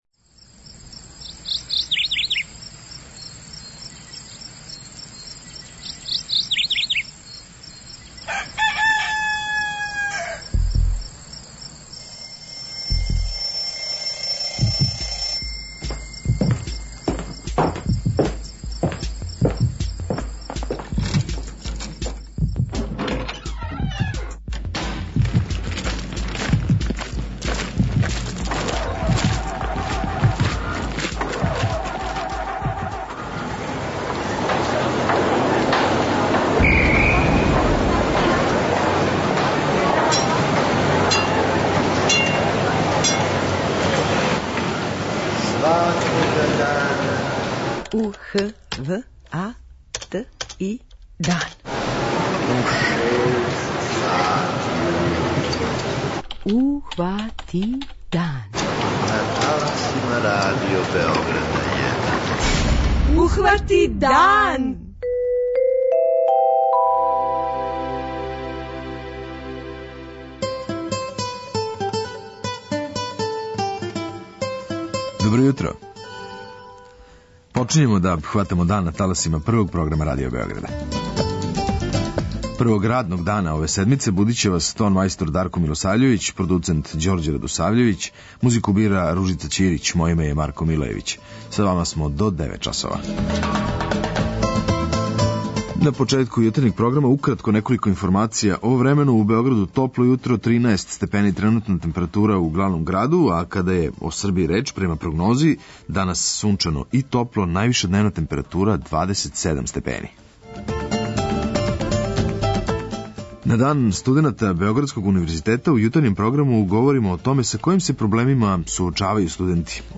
Због тога је важно да знамо како да се заштитимо од крпеља и шта да урадимо уколико их приметимо на кожи. Чућемо савете лекара и биолога.
преузми : 43.12 MB Ухвати дан Autor: Група аутора Јутарњи програм Радио Београда 1!